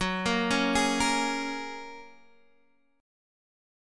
Gbadd9 chord